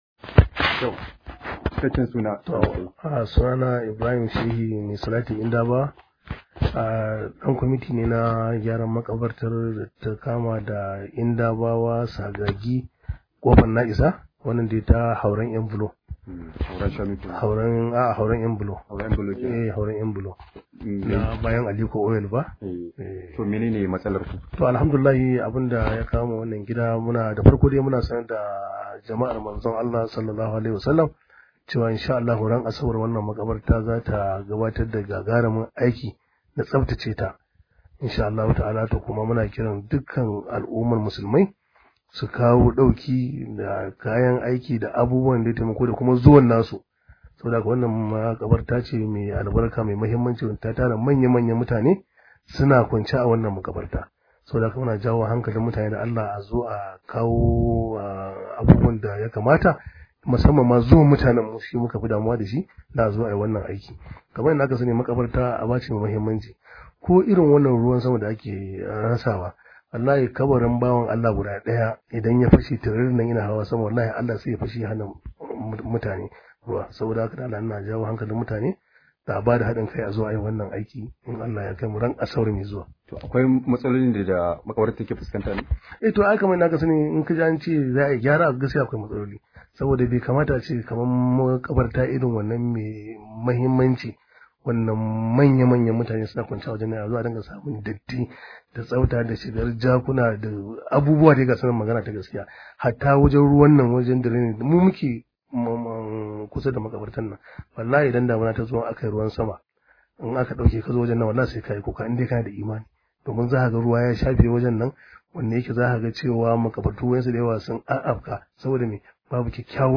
Rahoto: A kawo mana daukin gyaran makarbartar mu – Yankin Kofar Na’isa